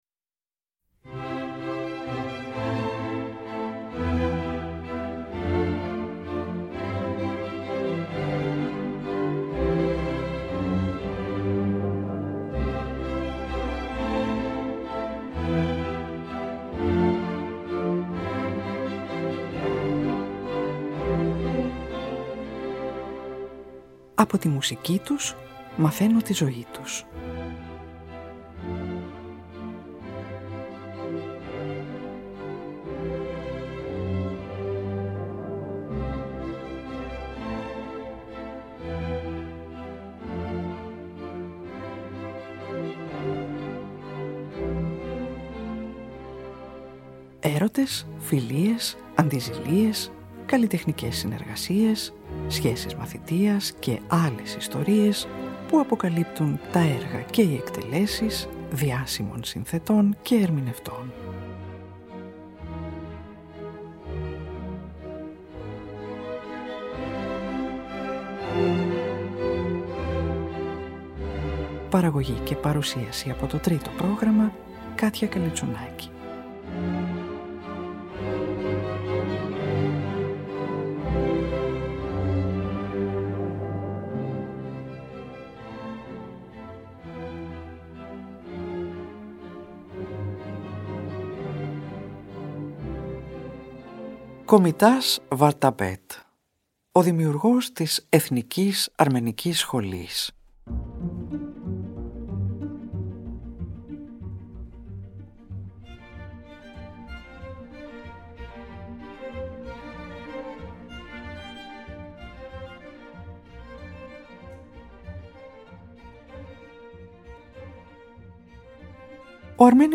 υψίφωνος
πιανίστα
το σύνολο εγχόρδων
παίζει 3 παραδοσιακά τραγούδια των συλλογών του
κιθάρα
σαξοφωνίστα